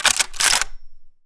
Guns_Weapons
gun_rifle_cock_03.wav